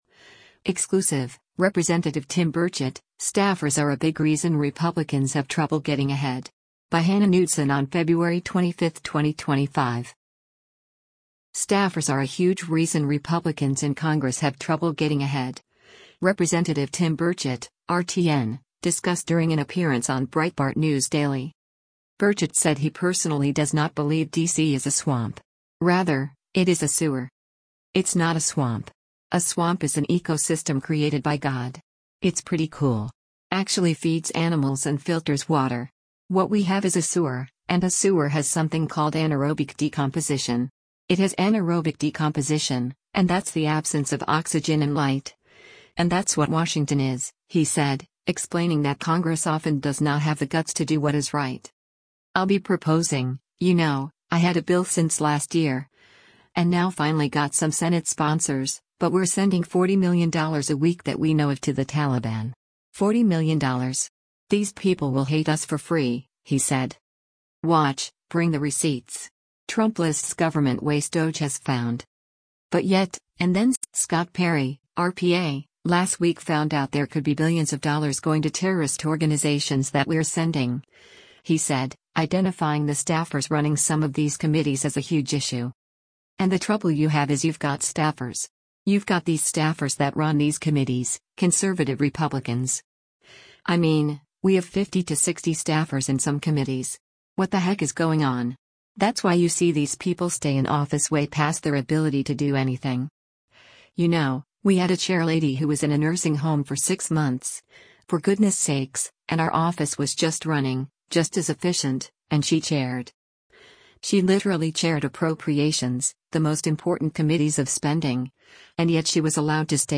Staffers are a huge reason Republicans in Congress have trouble getting ahead, Rep. Tim Burchett (R-TN) discussed during an appearance on Breitbart News Daily.
Breitbart News Daily airs on SiriusXM Patriot 125 from 6:00 a.m. to 9:00 a.m. Eastern.